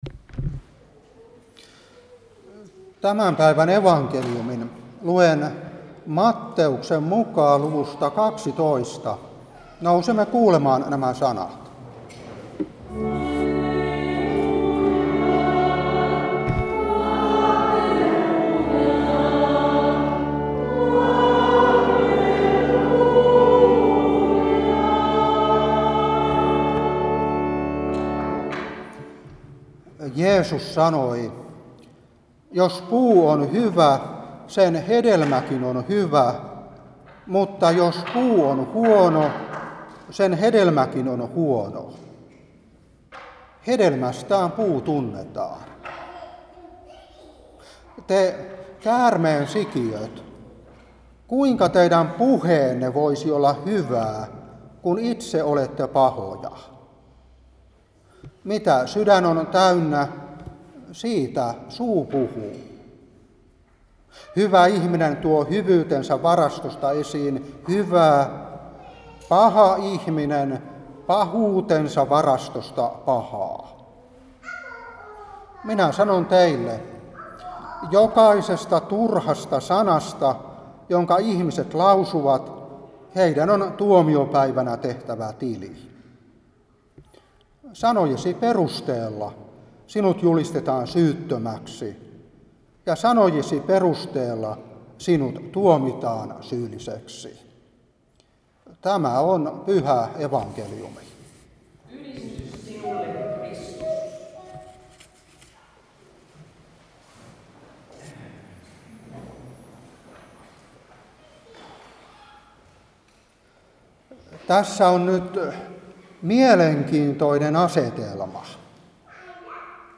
Saarna 2023-8.